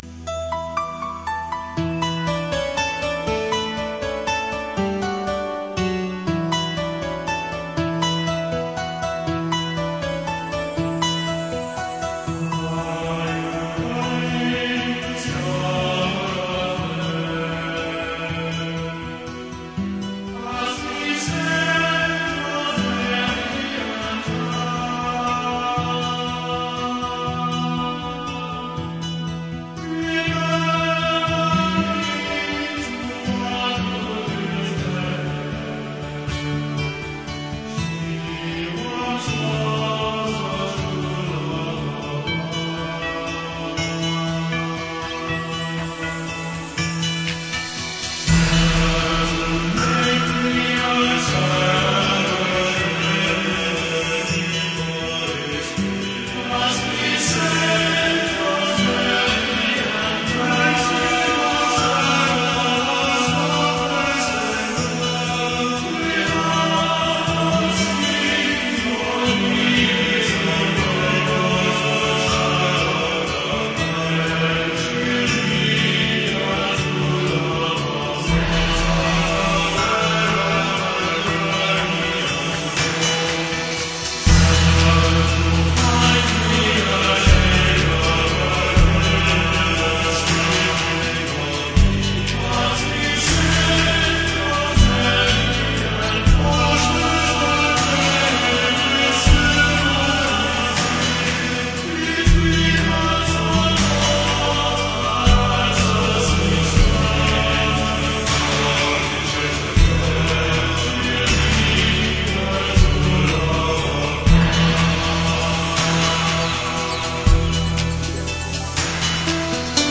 NEW! Super, to tu ještě nebylo ukázka prní skladby z nového cd.